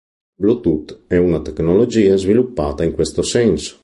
Pronunciato come (IPA) /bluˈtut/